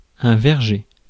Ääntäminen
IPA : /bɝtʃ/ US : IPA : [bɝtʃ]